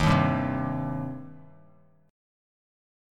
D#6 Chord
Listen to D#6 strummed